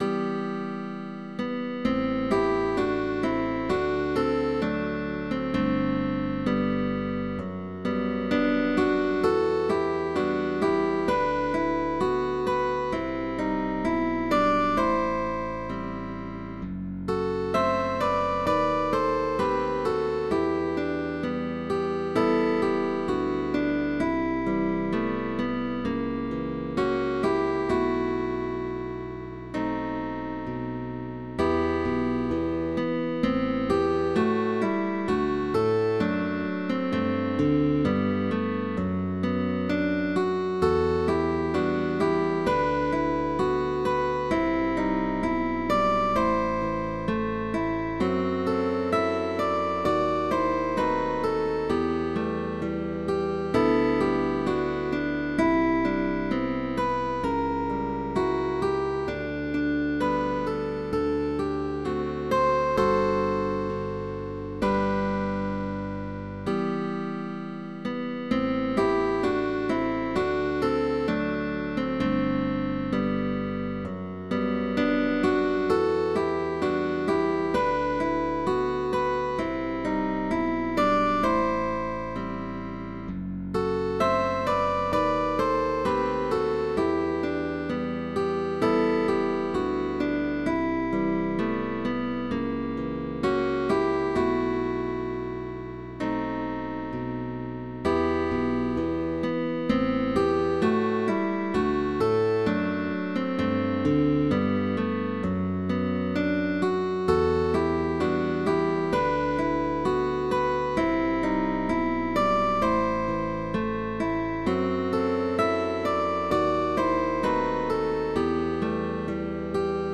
TRÍO DE GUITARRAS
Cambios de posición, ligados, cejillas,…Arpegios y acordes.